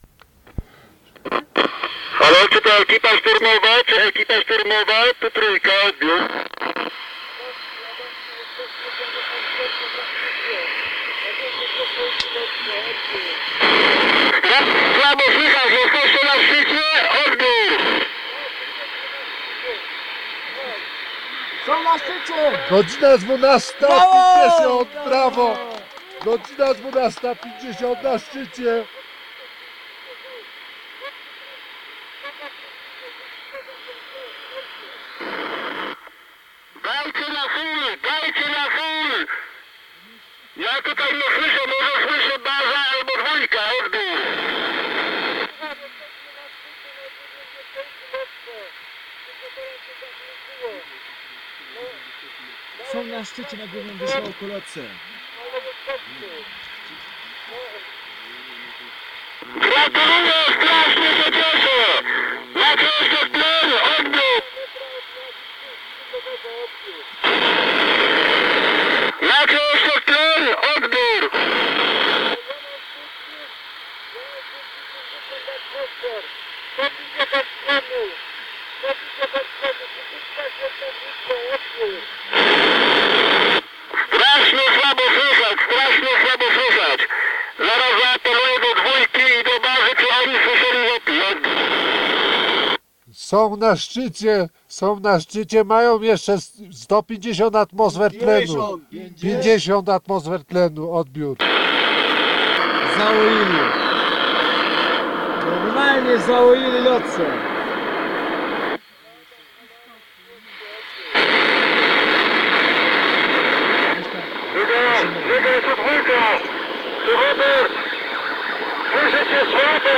Przykładowo w ubiegłym roku dotarliśmy do nagrań łączności radiowej z dwóch bardzo ważnych w polskiej historii wypraw – na Lhotse w 1979 roku oraz Makalu 1982. Obie wyprawy zakończyły się wielkimi sukcesami, a dziś dzięki przeprowadzonej cyfryzacji i zabezpieczeniu kaset (na które składa się 31 godzin nagrań z wypraw!) możemy usłyszeć radość himalaistów z momentu zdobycia szczytu, czy posłuchać jak przebiegała komunikacja pomiędzy wspinaczami w trakcie wyprawy.
Poniżej zapis dźwiękowy z momentu zdobycia szczytu Lhotse